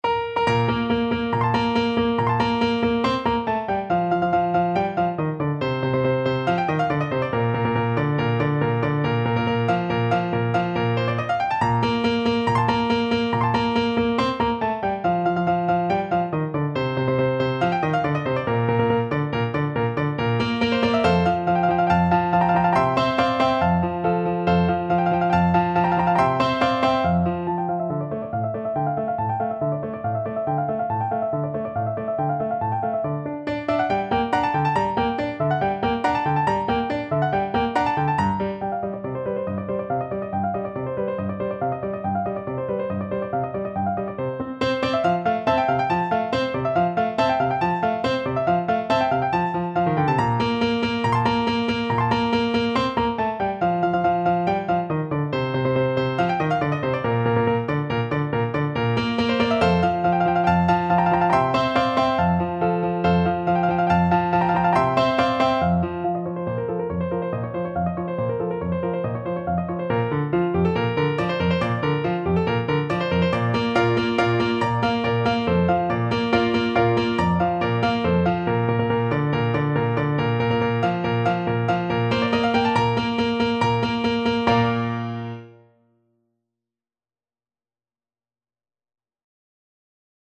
Play (or use space bar on your keyboard) Pause Music Playalong - Piano Accompaniment Playalong Band Accompaniment not yet available transpose reset tempo print settings full screen
Bb major (Sounding Pitch) C major (Trumpet in Bb) (View more Bb major Music for Trumpet )
~ = 140 Allegro vivace (View more music marked Allegro)
2/4 (View more 2/4 Music)
Classical (View more Classical Trumpet Music)